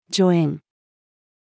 助演-female.mp3